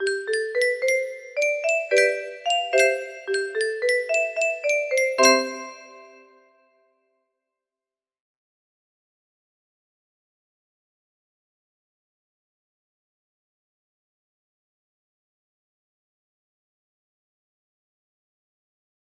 LaBamba music box melody